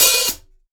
Closed Hats
TC3Hat1.wav